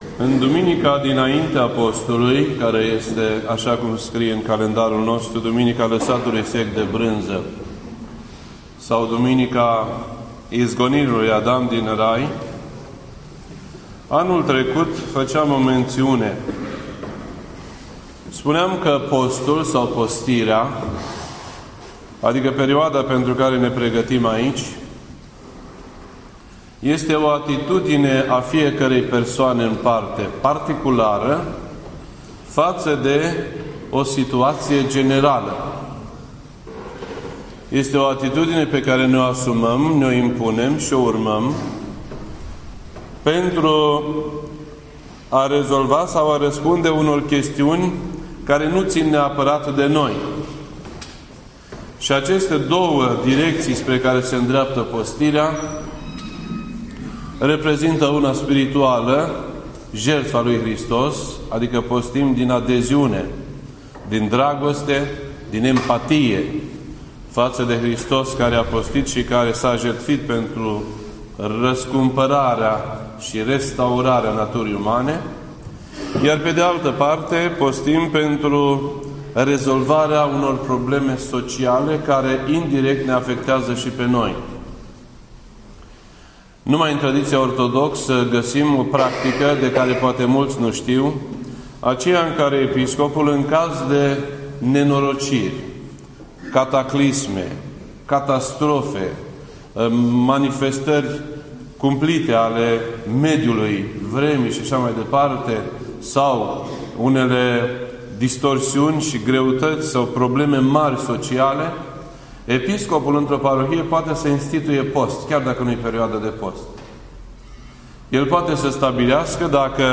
This entry was posted on Sunday, February 22nd, 2015 at 9:14 PM and is filed under Predici ortodoxe in format audio.